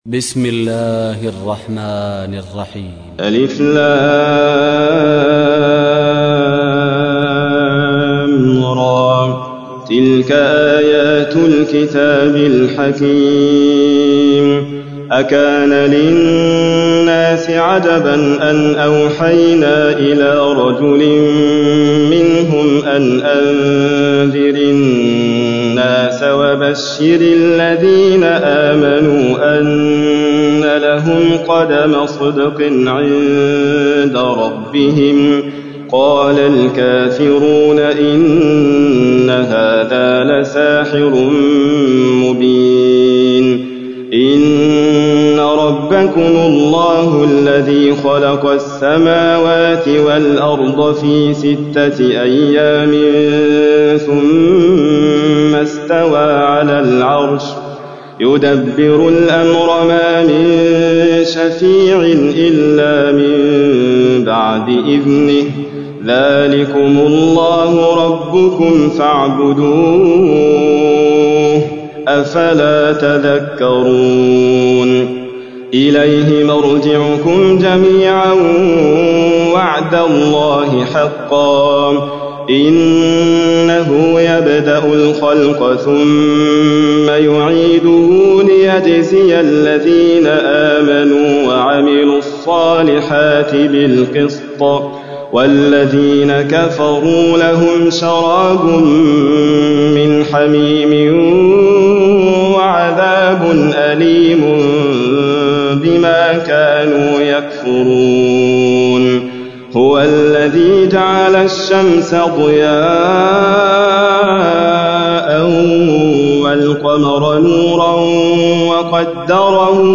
تحميل : 10. سورة يونس / القارئ حاتم فريد الواعر / القرآن الكريم / موقع يا حسين